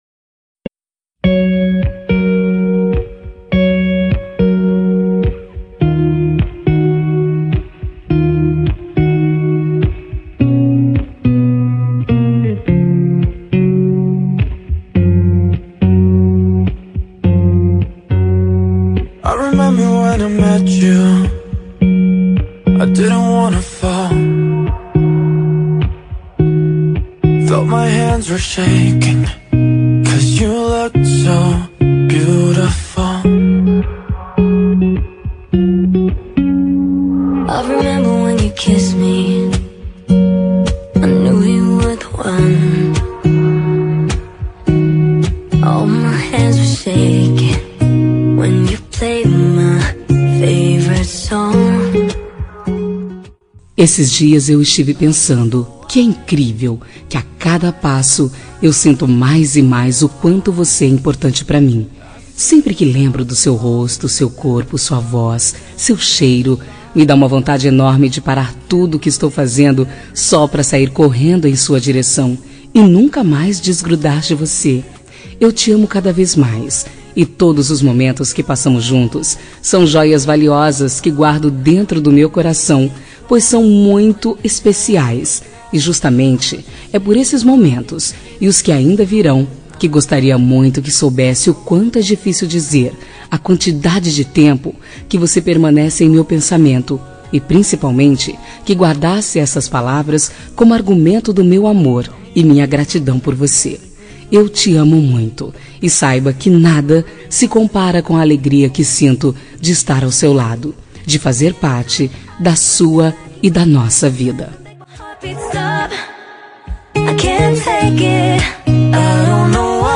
Telemensagem Romântica para Marido – Voz Feminina – Cód: 9064